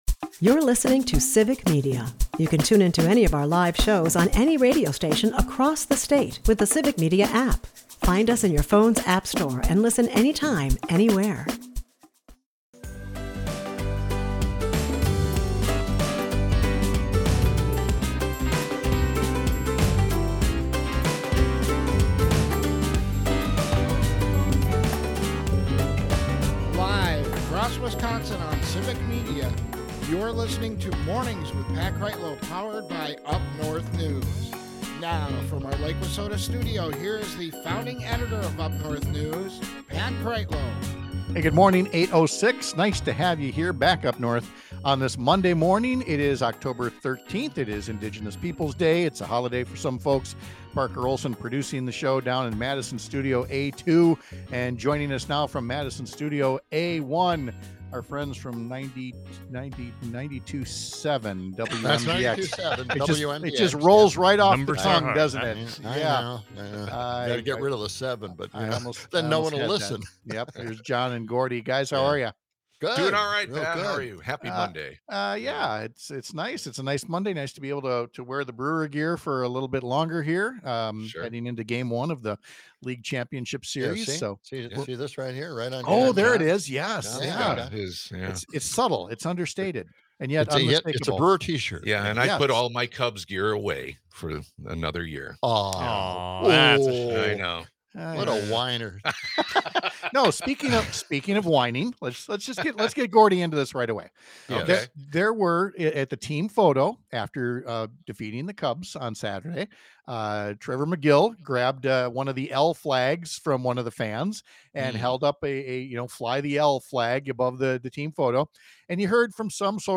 2025 44:10 Listen Share We’ll talk to Rep. Jodi Emerson about her new package of bills designed to support higher education in Wisconsin, which sounds like common sense – but then you remember what passes for “support” for education on the other side of the aisle and get a better appreciation for what lawmakers like Emerson are trying to do.